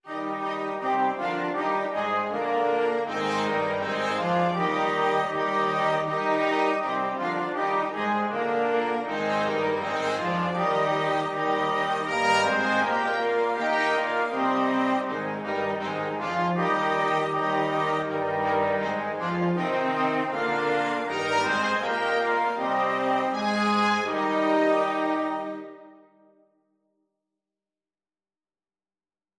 Christmas Christmas Flexible Mixed Ensemble
Allegro = c.80 (View more music marked Allegro)
2/2 (View more 2/2 Music)